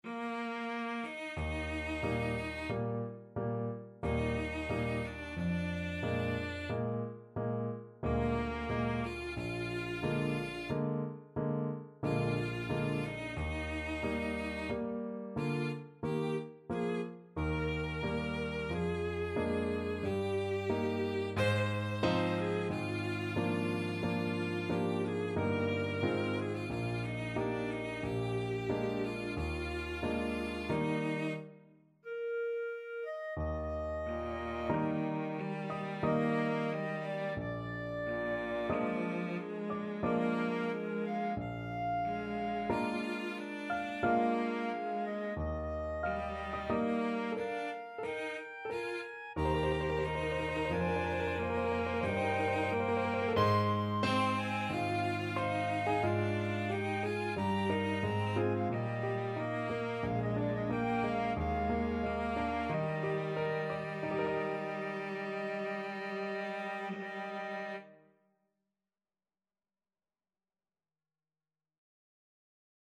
ClarinetCelloPiano
Adagio =45
Classical (View more Classical Mixed Trio Music)